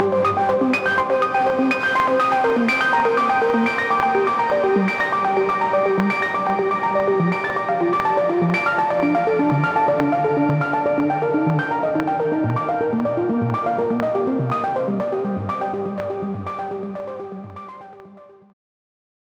Arpeggio_Loop_River.wav